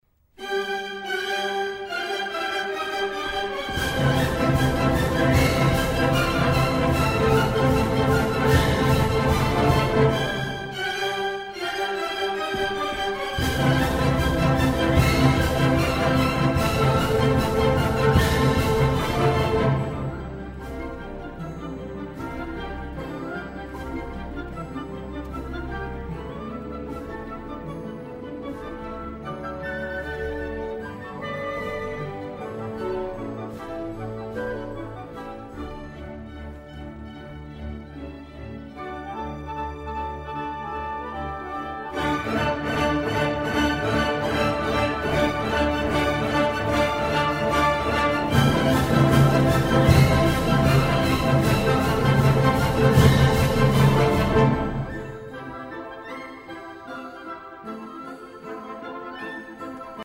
Antonin Dvorak - Slavonic Dance No. 7 in C Major, Op. 72 - Yo-Yo Ma